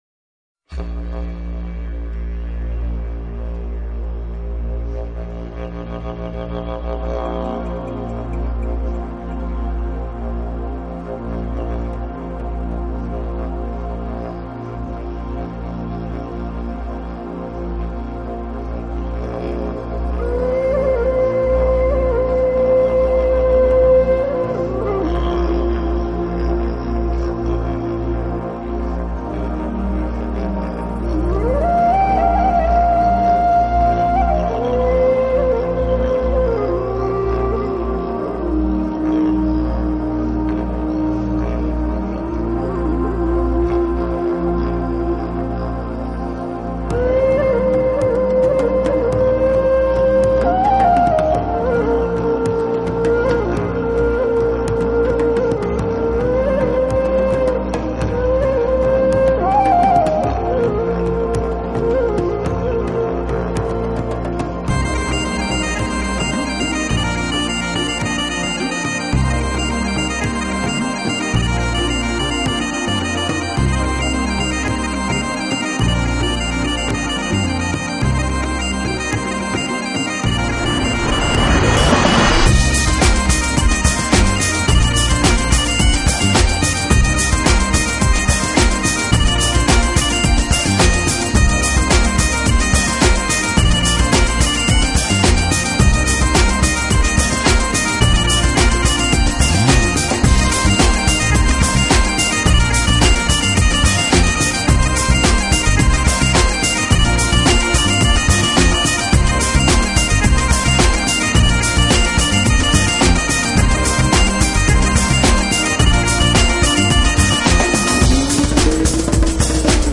音乐风格: Medieval Folk, Ethereal, New Age